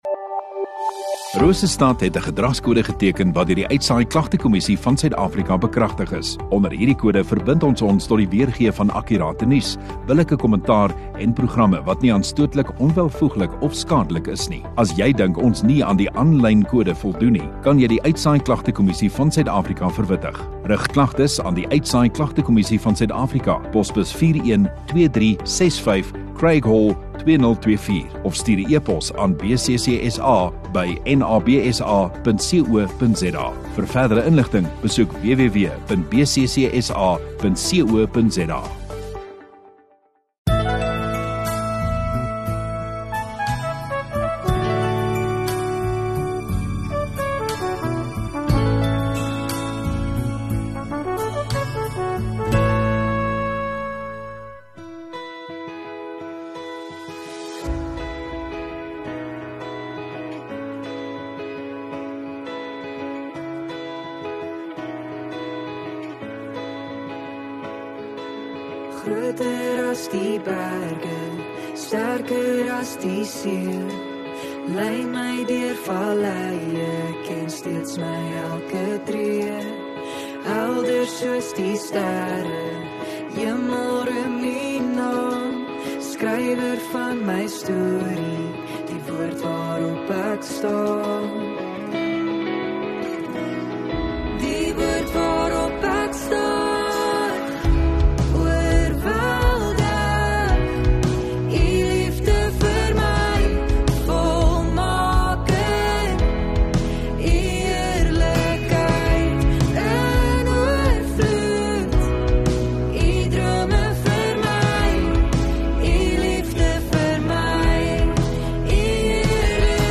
22 Feb Saterdag Oggenddiens